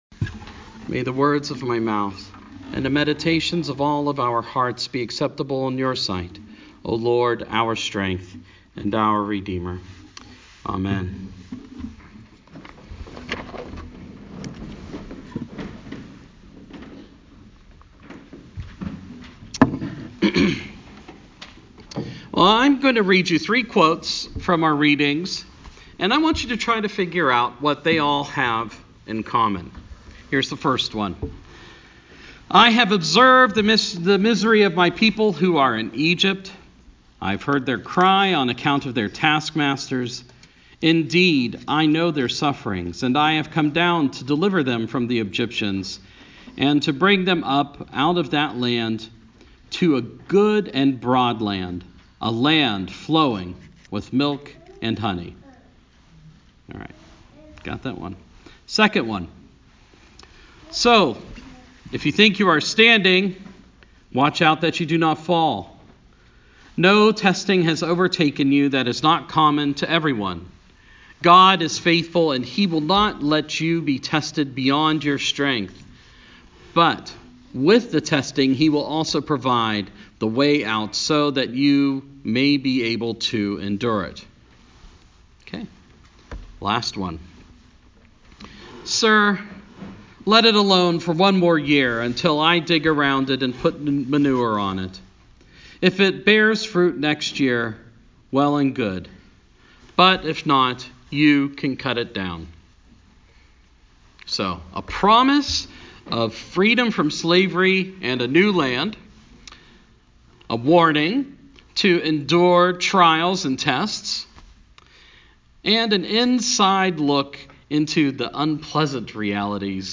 Third Sunday in Lent